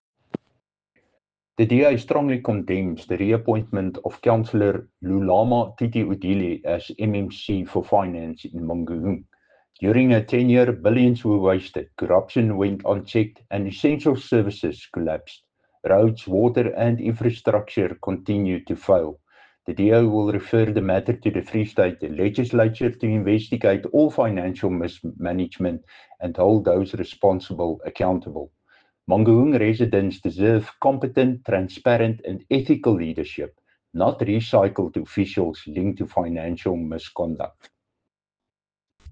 Afrikaans soundbites by Cllr Dirk Kotze and